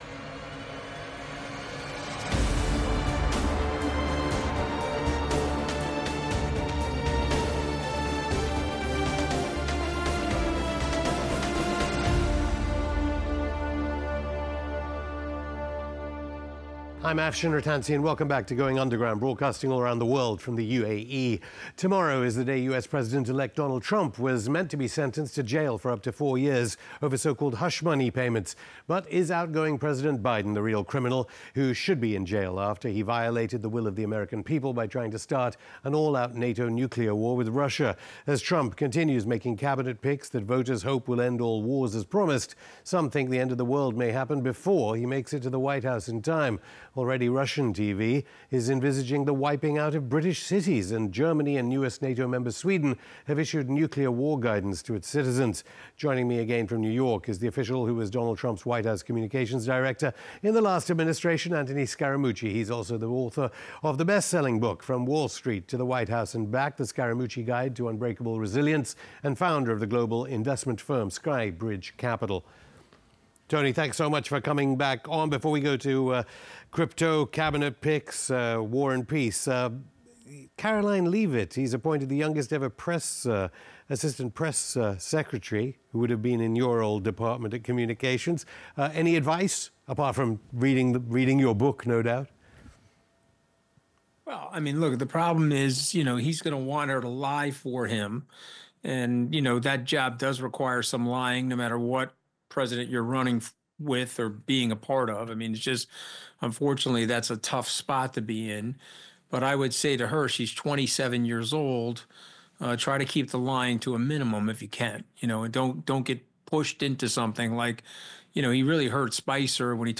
Anthony Scaramucci: ‘Trump Will Surprise People on Ukraine’, Will He Bring a Crypto Boom? (Afshin Rattansi interviews Anthony Scaramucci; 25 Nov 2024) | Padverb